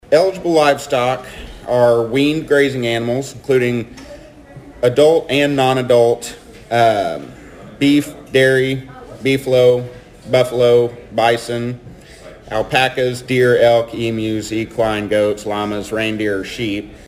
The Thayer and Mammoth Spring Rotary met Wednesday for their weekly meeting.